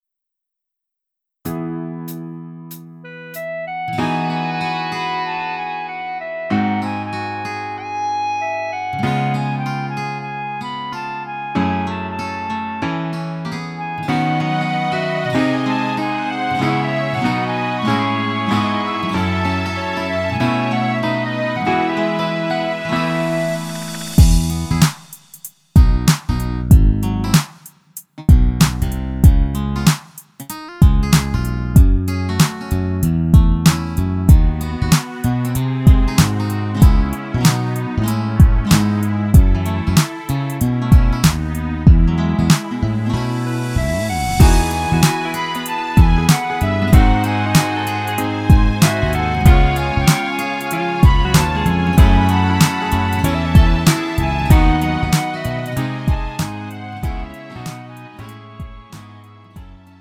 음정 원키 3:14
장르 가요 구분 Lite MR